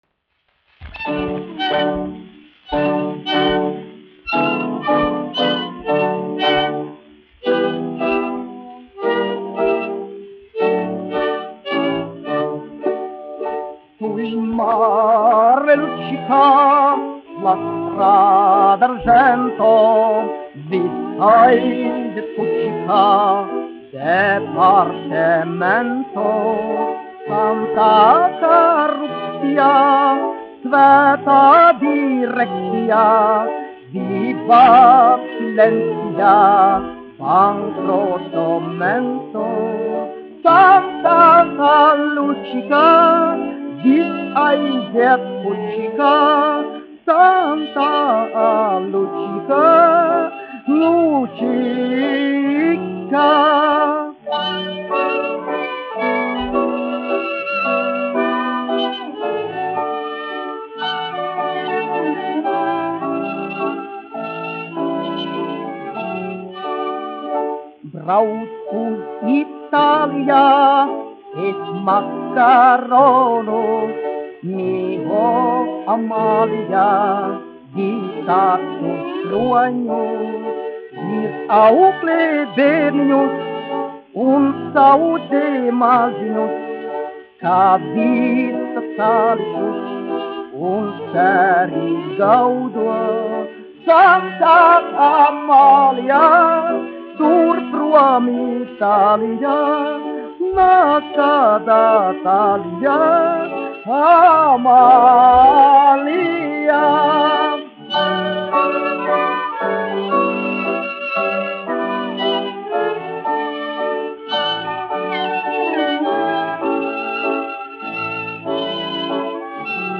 1 skpl. : analogs, 78 apgr/min, mono ; 25 cm
Populārā mūzika -- Itālija
Humoristiskās dziesmas
Skaņuplate